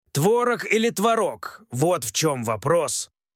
Озвучка большого текста онлайн